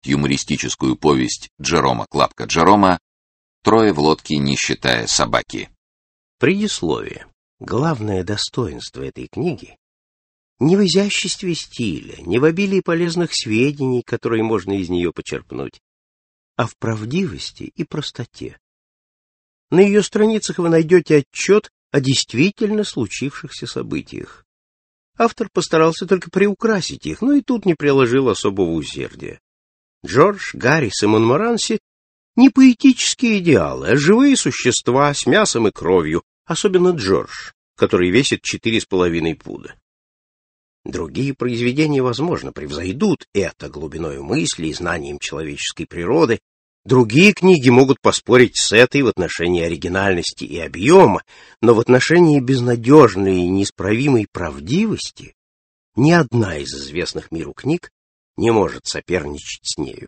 Аудиокнига Трое в лодке, не считая собаки | Библиотека аудиокниг
Прослушать и бесплатно скачать фрагмент аудиокниги